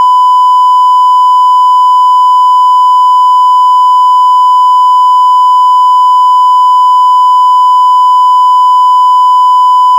sine-triangle.mp3